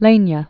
(lānyə, lĕn-), Lotte Originally Karoline Wilhelmine Blamauer. 1898-1981.